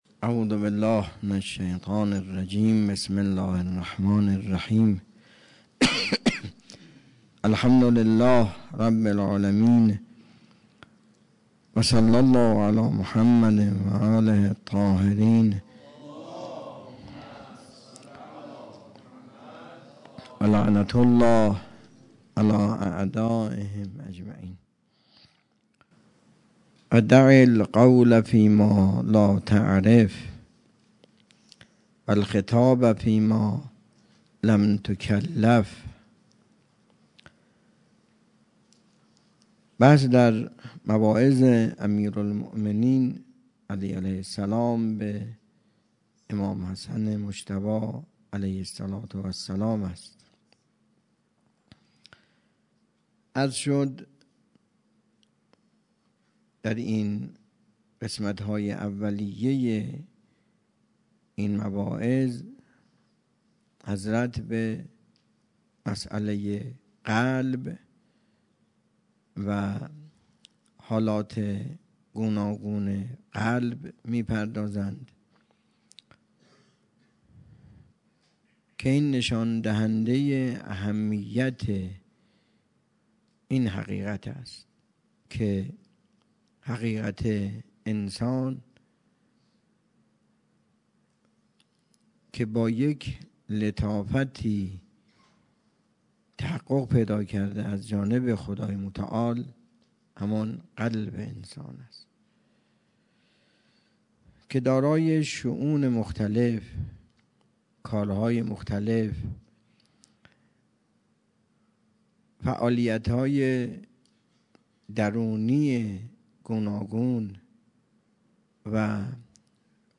درس اخلاق
حوزه علمیه مروی سخنرانی